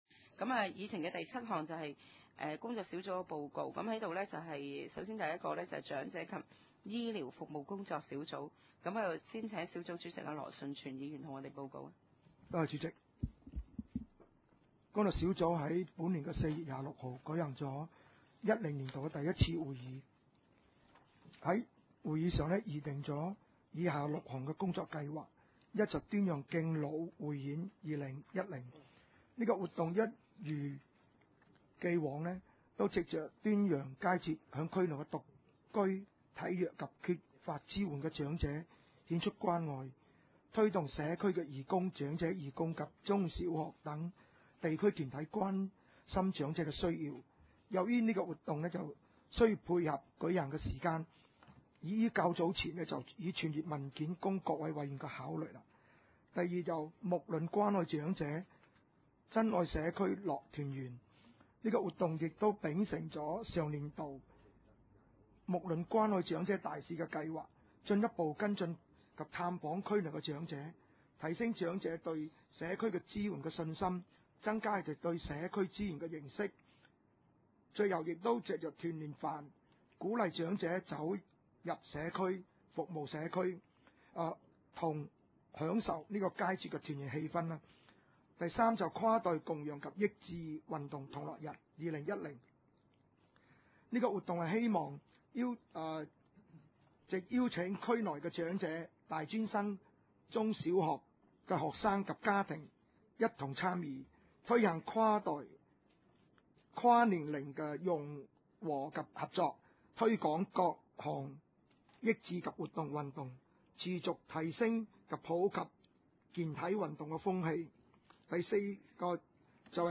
大埔區議會社會服務委員會2010年第三次會議
地點：大埔區議會秘書處會議室